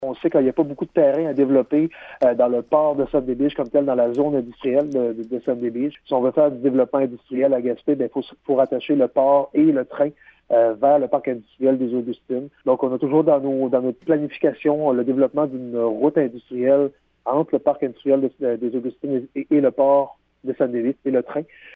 Écoutez l’entrevue complète avec le maire Daniel Côté.